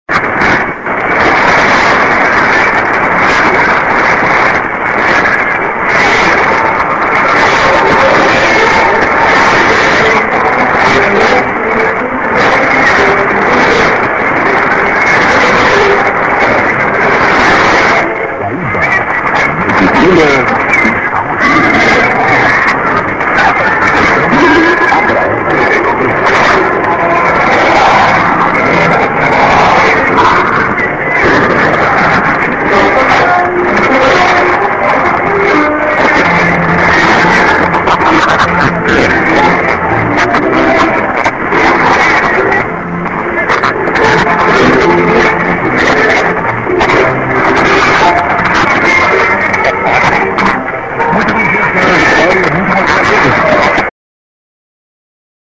98/08/15 19:00 6,000　 115　 poor
->20":ID(man)->　再度編集します。
最初にノイズが続く　終わりはサイドが強く聞きにくい。でもタイミング良くＩＤは取れます。